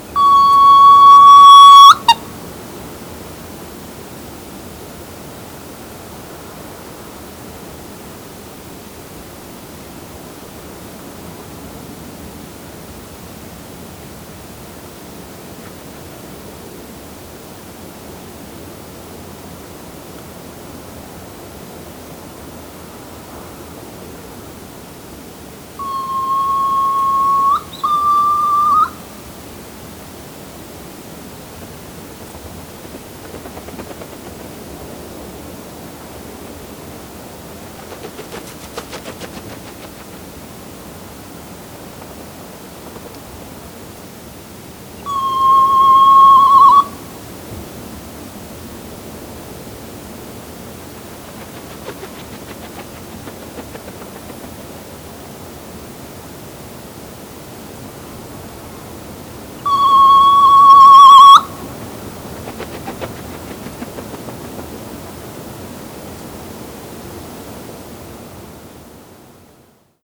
Pterodroma mollis - Petrel collar gris
Petrel collar gris.wav